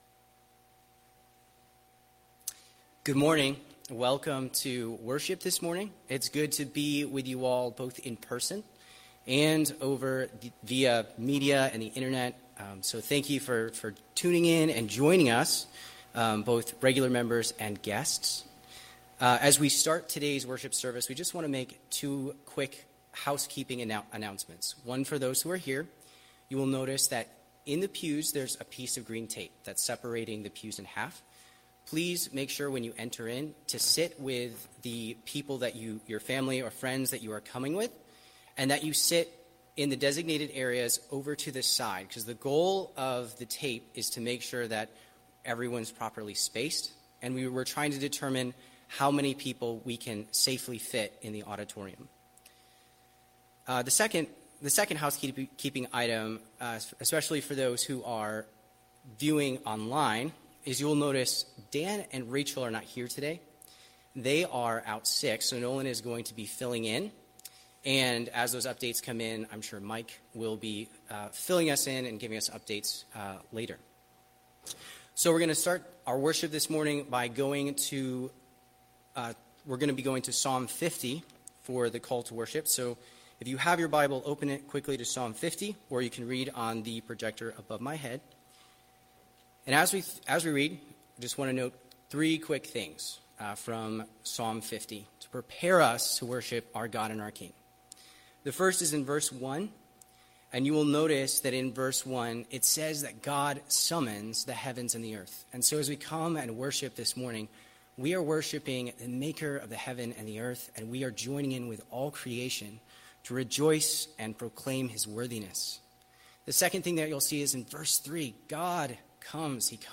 Series The Gospel of Luke Service Morning Worship